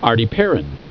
Pronunciation
(ar dee PA rin)